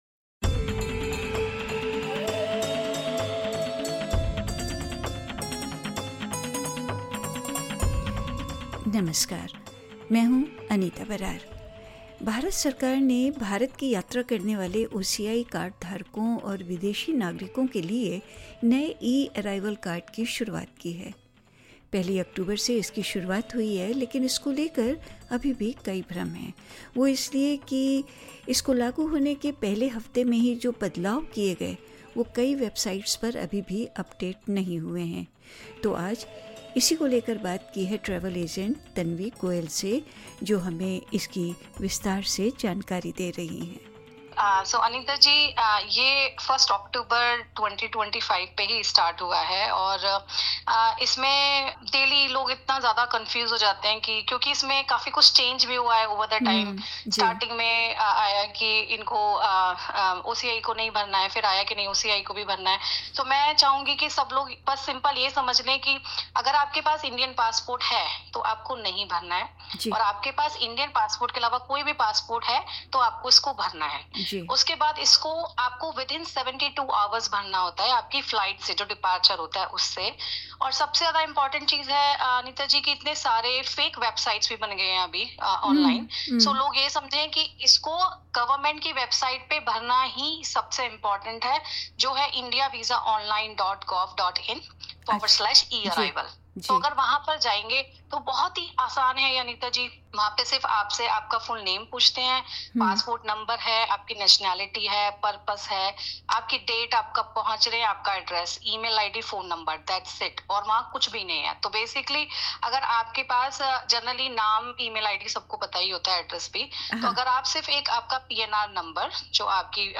Disclaimer: The information in this interview is about the introduction of the e-Arrival Card by the Government of India and is current at the time of this podcast.